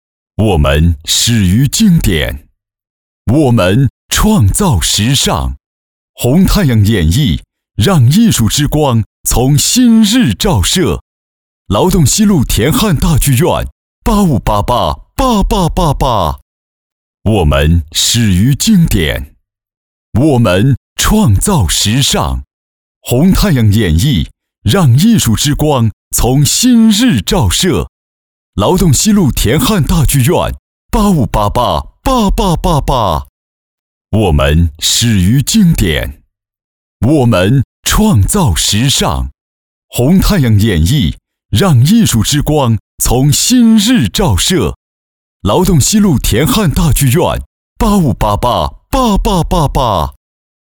• 男1 国语 男声 【广告】红太阳演绎 沉稳大气 激情激昂|大气浑厚磁性